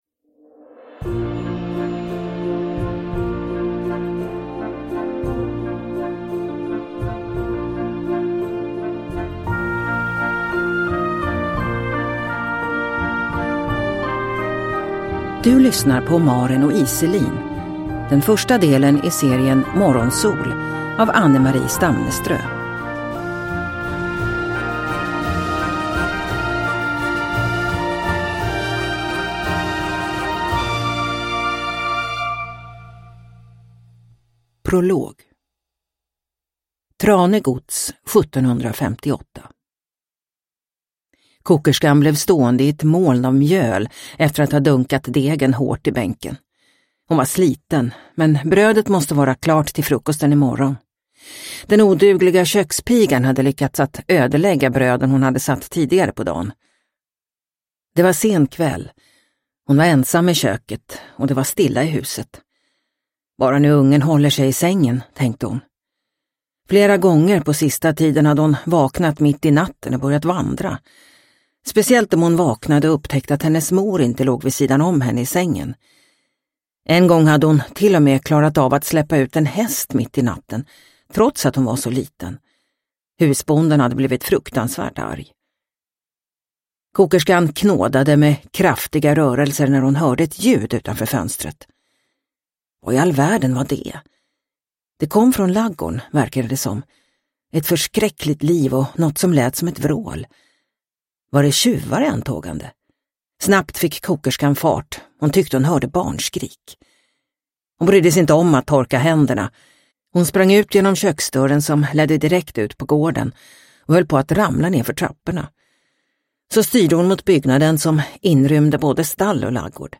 Maren och Iselin – Ljudbok – Laddas ner